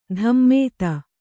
♪ dhammēta